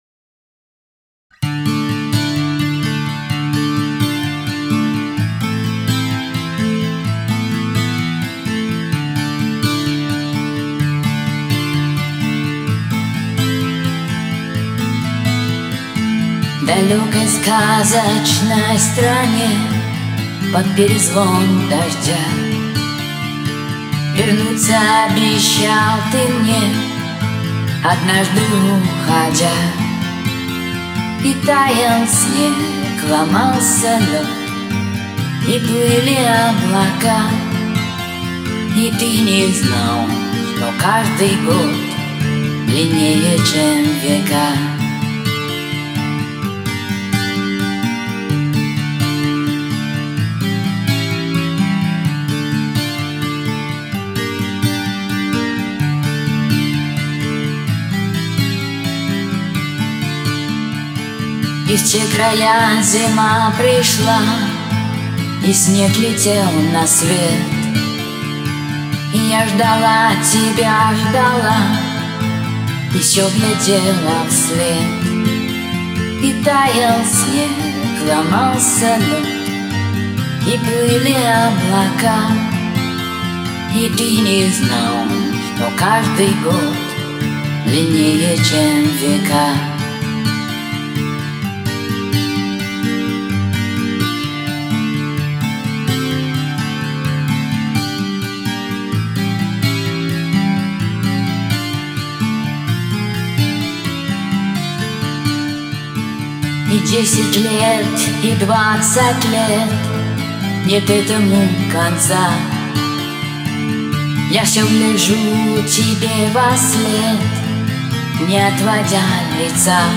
гитара
Послушайте мужской вариант исполнения.